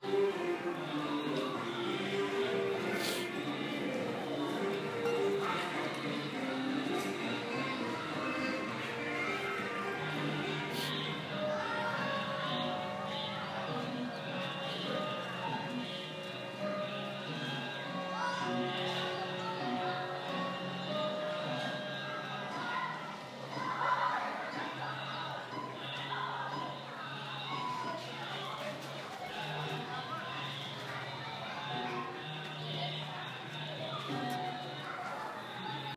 거기에 학교종이 땡땡땡 소리가 흐른다면.